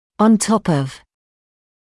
[ɔn tɔp][он топ]на верхней точке; сверху; вдобавок